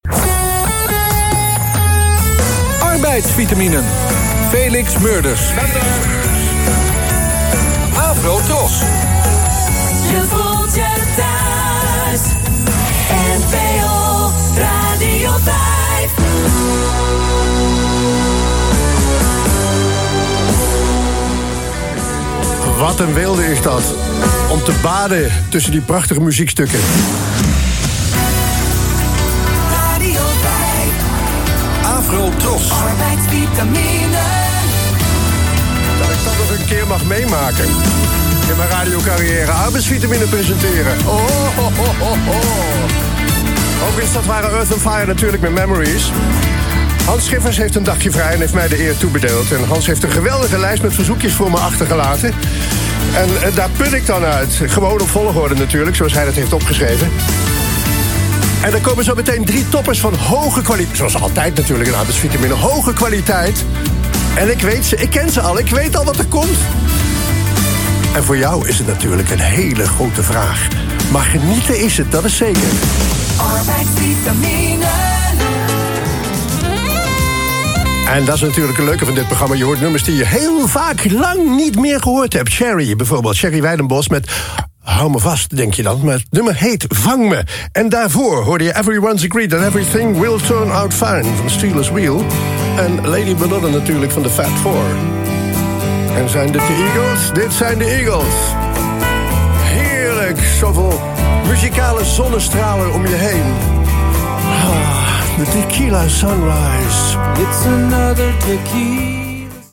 Ouderwets grappig en gezellig.